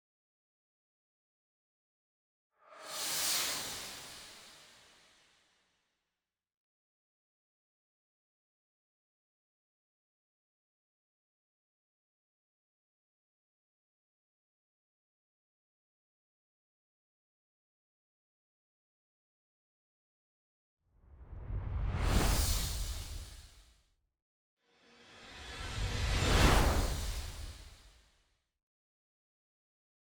MJ_MomentinTime_ST_SFX.wav